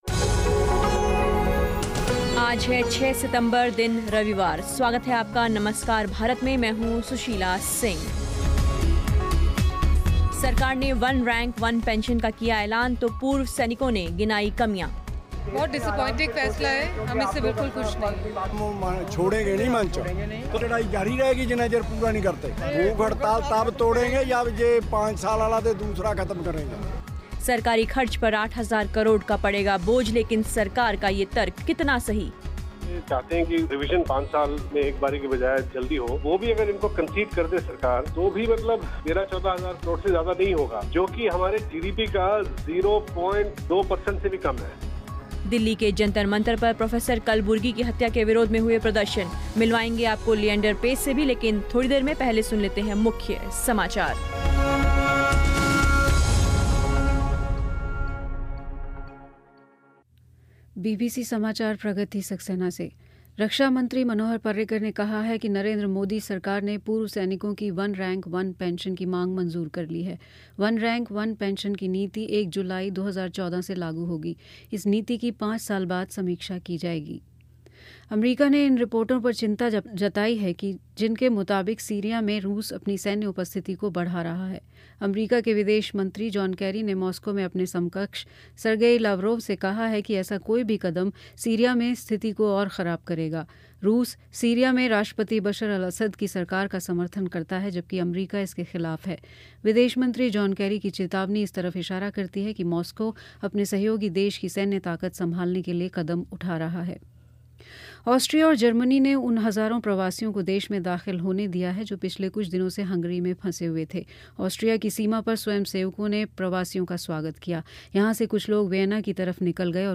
दिल्ली के जंतर मंतर पर प्रोफ़ेसर कलबुर्गी की हत्या के विरोध में हुए प्रदर्शन सुनिए जीतनराम मांझी से ख़ास बातचीत